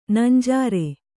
♪ nanjāre